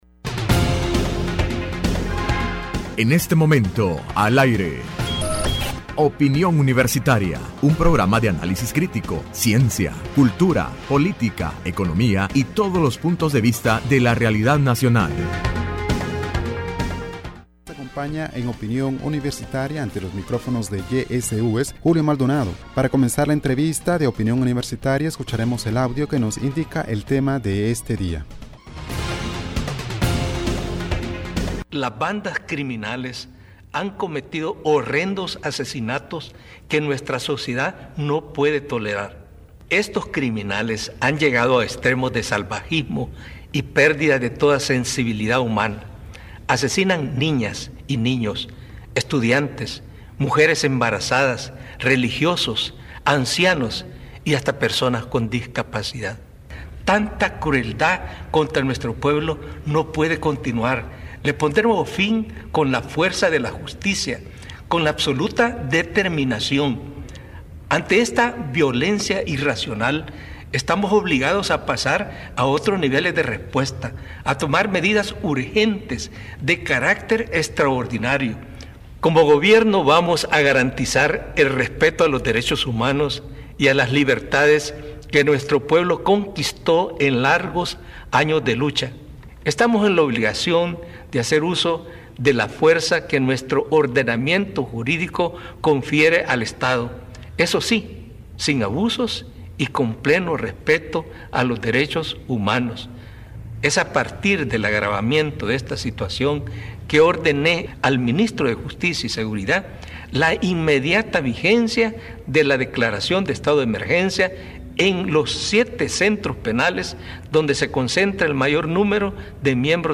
Entrevista Opinión Universitaria(4 de Abril 2016) : Análisis sobre medidas de seguridad implementadas por el Gobierno de El Salvador, para el combate a la delincuencia.